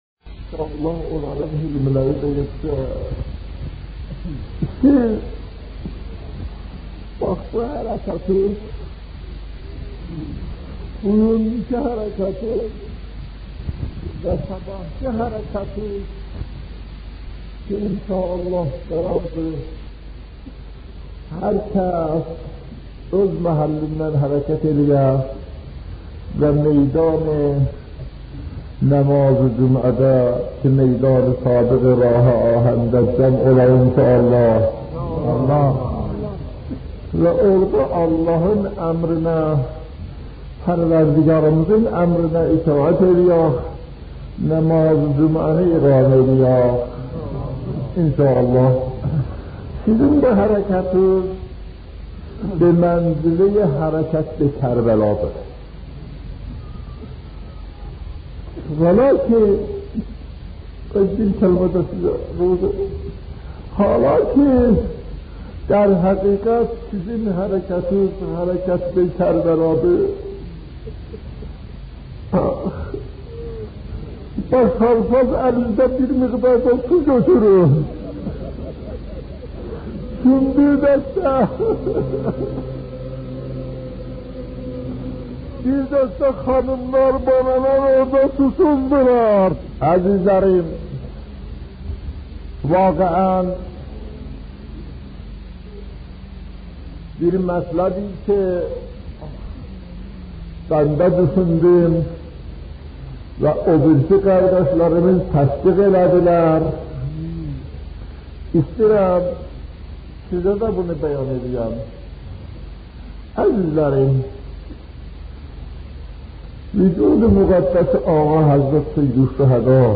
سخنرانی ترکی